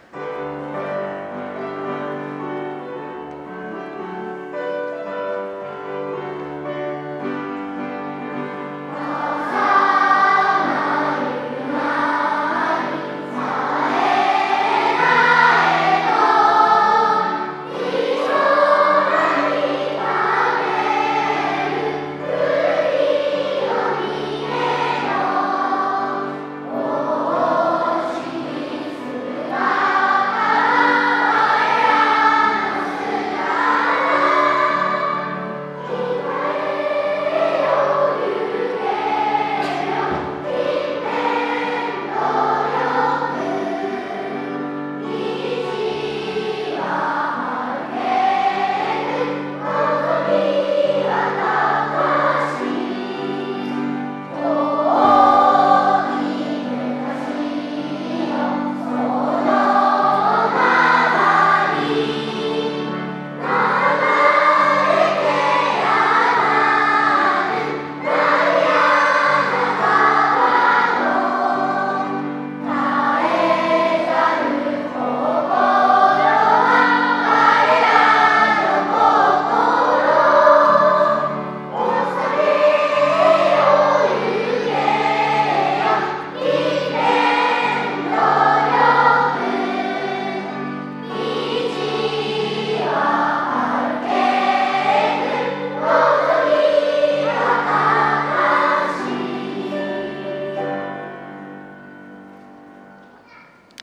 城崎小学校 校歌 ここをクリックすると校歌を聞けます → 〔令和元年 11月 全校合唱〕 作詞 白瀧五郎(第２代城崎小学校校長) 作曲 山本 正夫